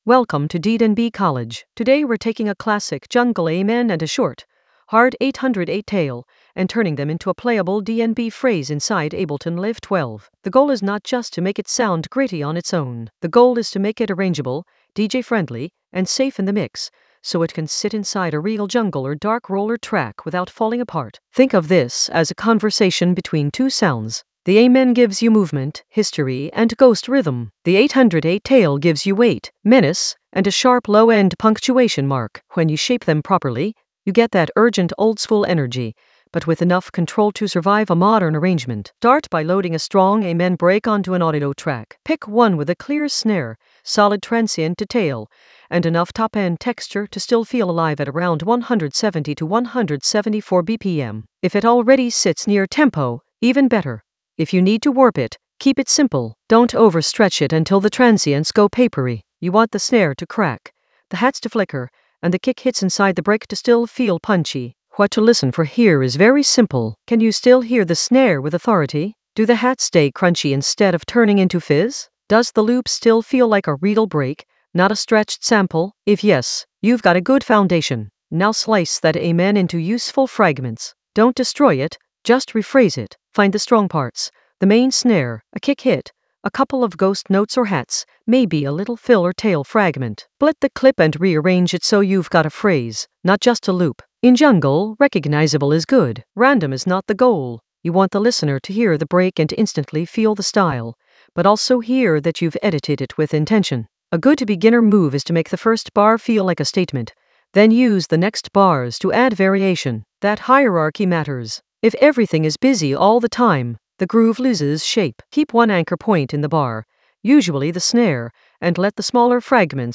An AI-generated beginner Ableton lesson focused on Midnight Amen a jungle 808 tail: flip and arrange in Ableton Live 12 for jungle oldskool DnB vibes in the Mixing area of drum and bass production.
Narrated lesson audio
The voice track includes the tutorial plus extra teacher commentary.